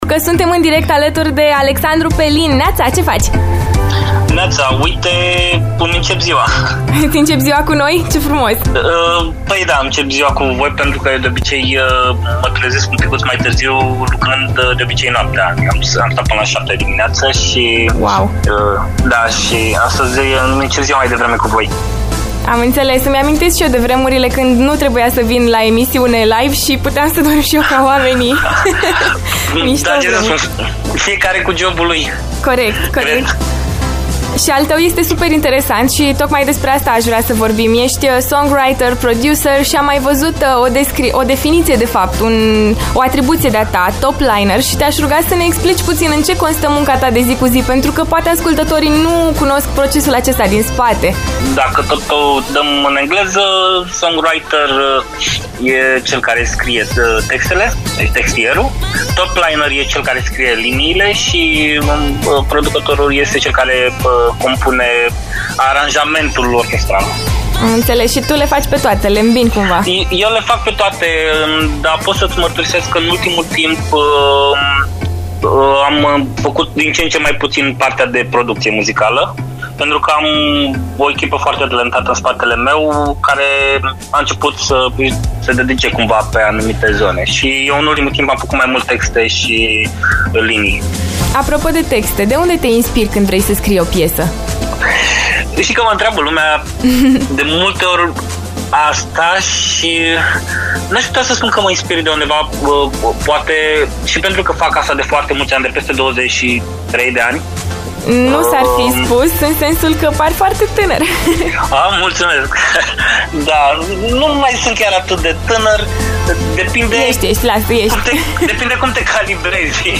Interviu LIVE